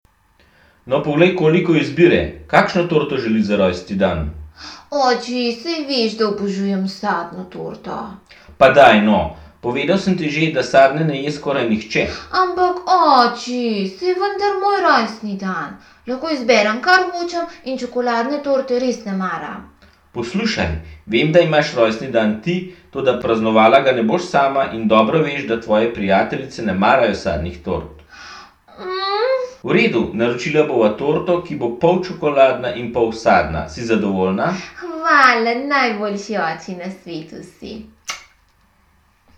Poslušaj tri pogovore, ki se odvijajo v slaščičarni, in bodi pozoren na to, v katerem pogovoru se bolj prepričuje, v katerem se bolj raziskuje in v katerem bolj pogaja.
Pogovor 2
V drugem pogovoru; oče in hči se pogajata o tem, katero torto bi kupila.